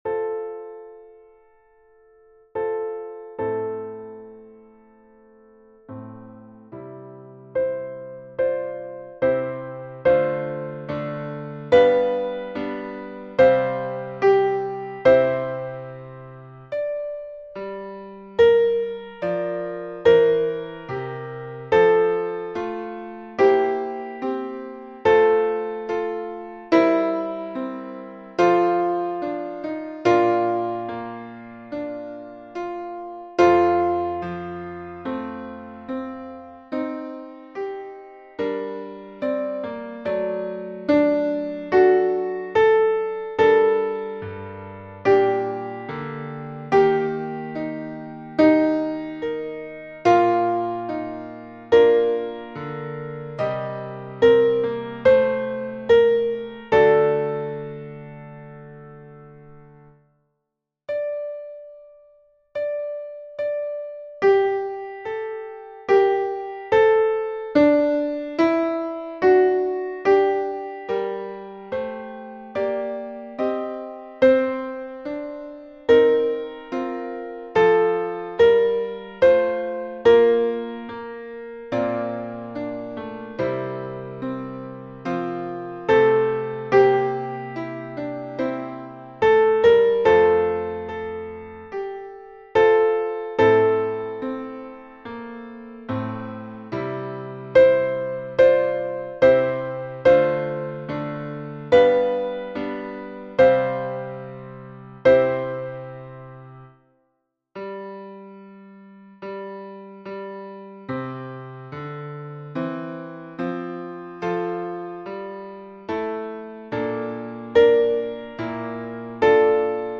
Mezzo Soprano (piano)